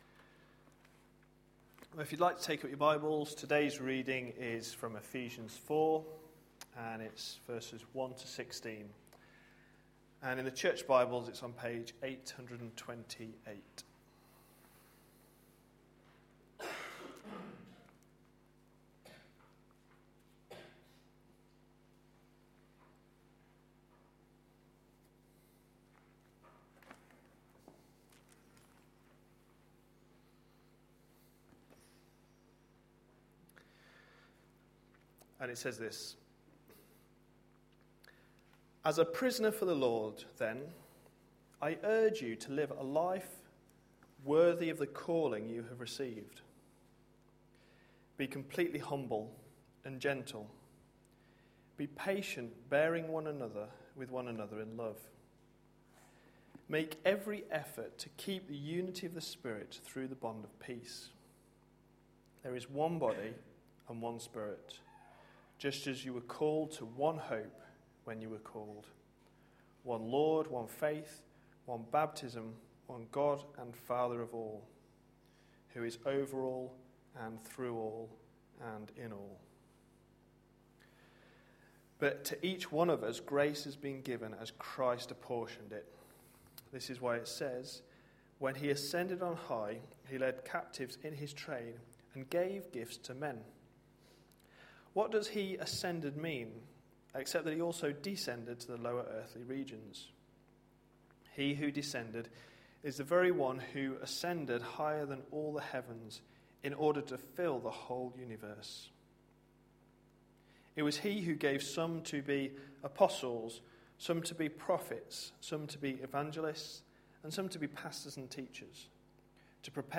A sermon preached on 16th June, 2013, as part of our Ephesians series.